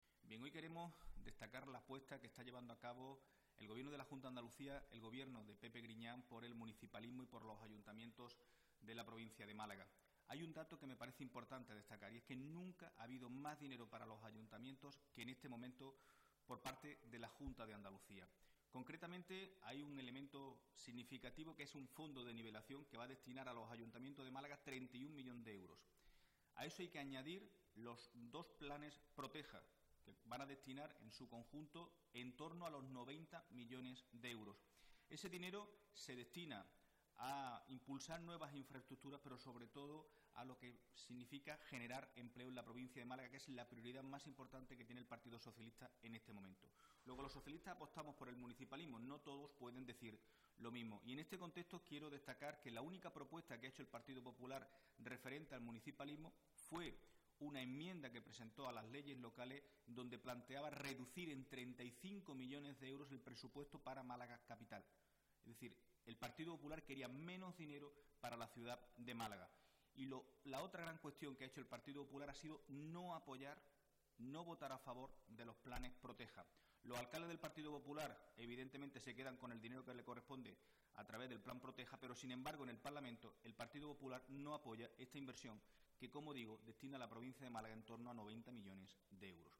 El secretario general del PSOE malagueño, Miguel Ángel Heredia, y el secretario de Comunicación del PSOE de Andalucía y secretario de Organización del PSOE de Málaga, Francisco Conejo, han valorado hoy en rueda de prensa la "apuesta municipalista" del Gobierno de Griñán.